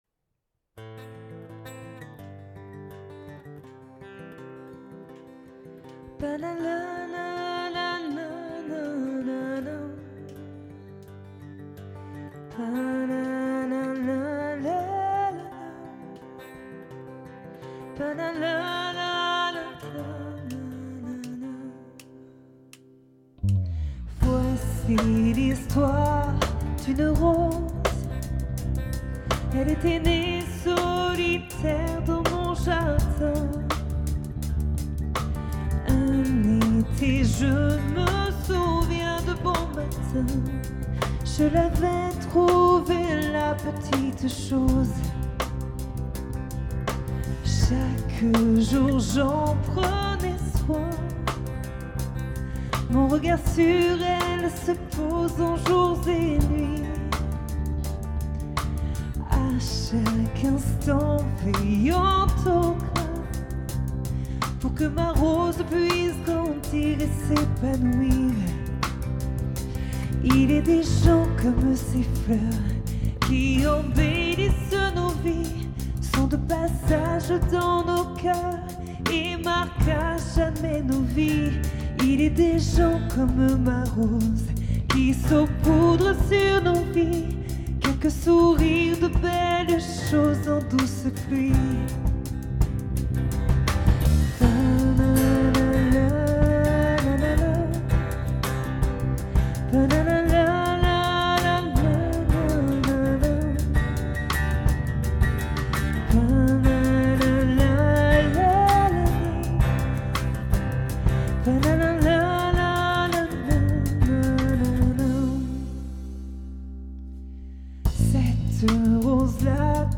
Cette chanson sera chantée en fin de concert du groupe.
Tout simple et à l'unisson.